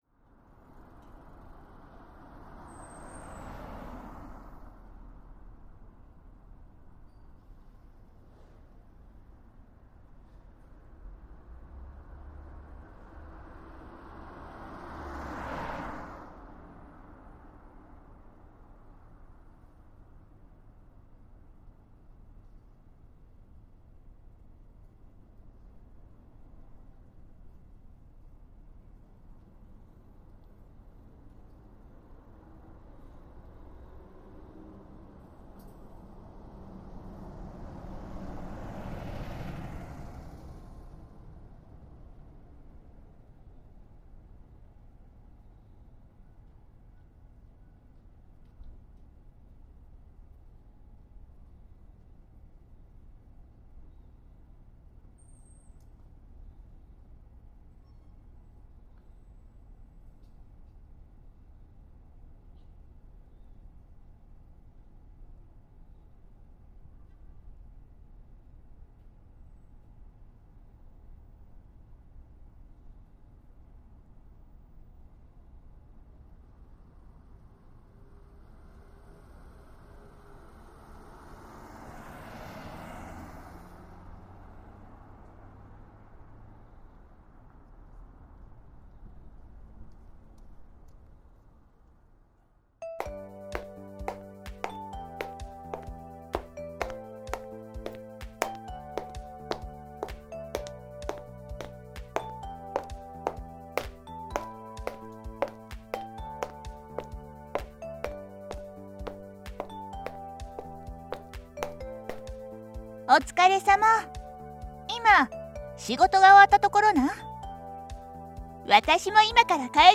関西弁彼女
関西弁彼女.wav